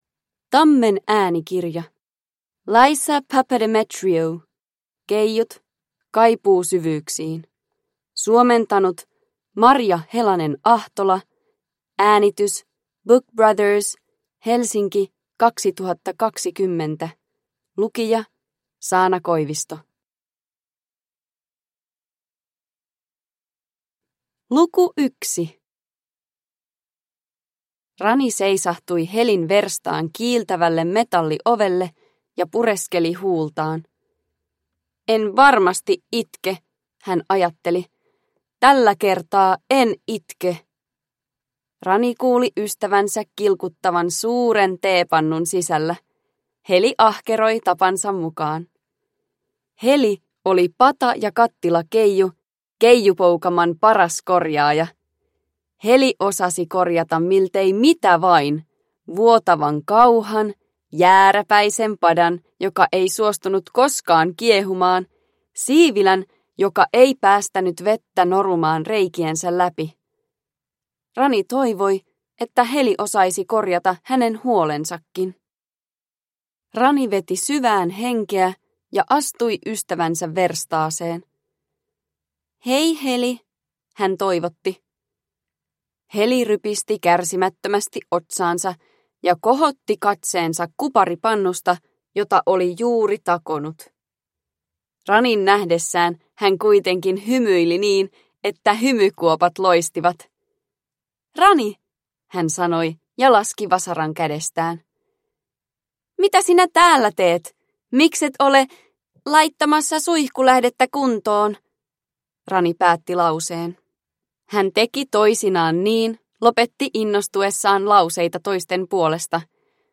Keijut. Kaipuu syvyyksiin – Ljudbok – Laddas ner